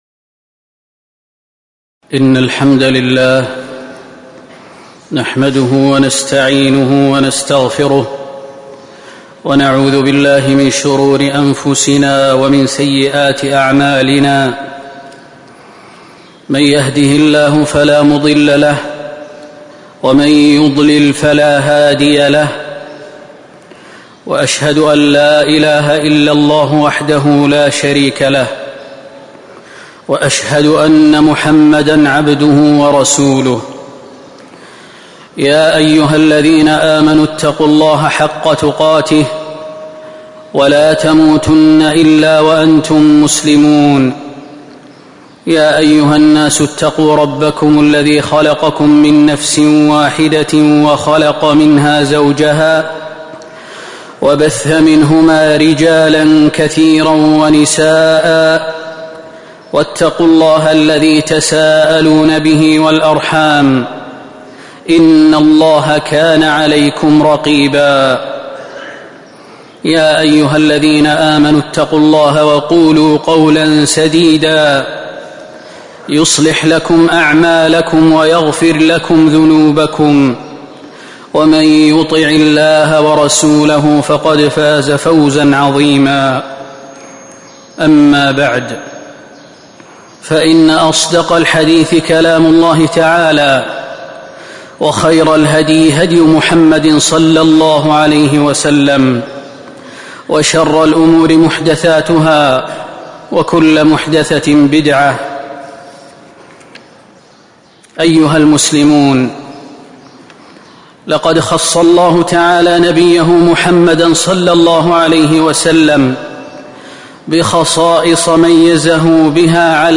تاريخ النشر ٢ صفر ١٤٤٥ المكان: المسجد النبوي الشيخ: فضيلة الشيخ خالد المهنا فضيلة الشيخ خالد المهنا الصدقة فضائل وحكم